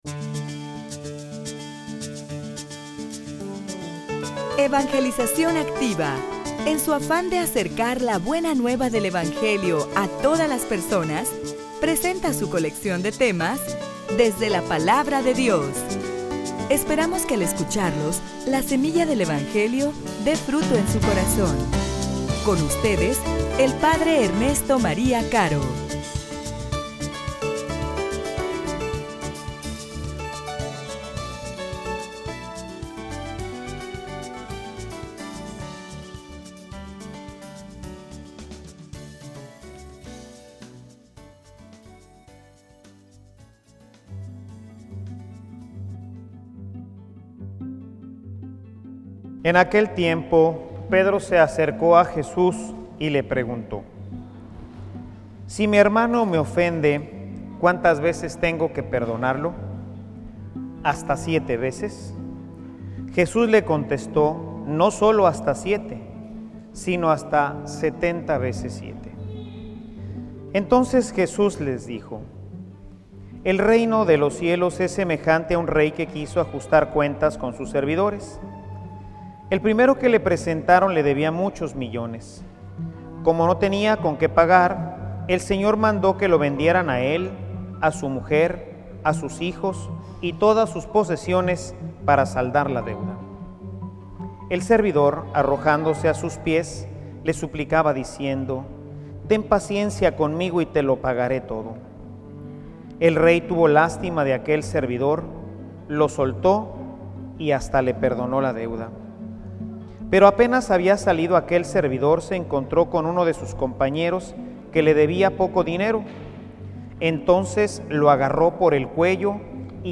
homilia_El_perdon_que_sana.mp3